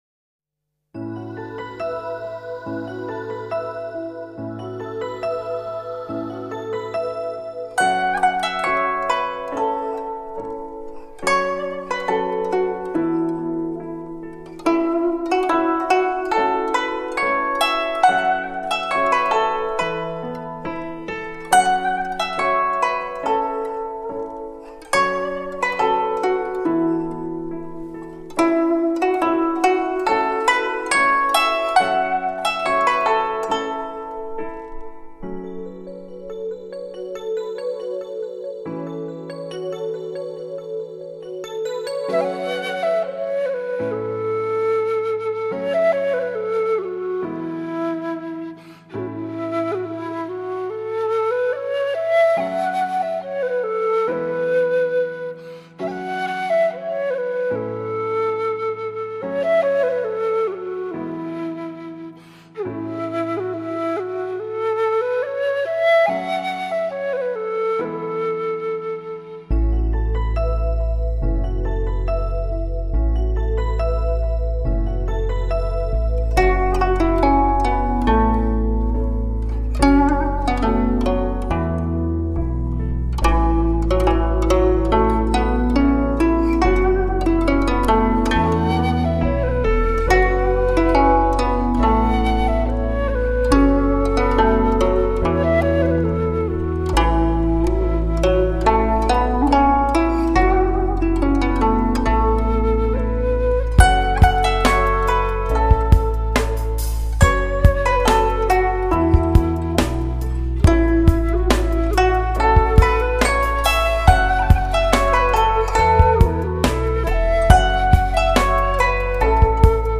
唱片类型：民族音乐
洞穿时空的筝笛合韵，绕指柔刻万古情愁，HD直刻无损高音质音源技术，HIFI限量珍藏版。